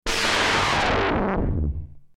KART_turboStart.mp3